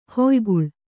♪ hoyguḷ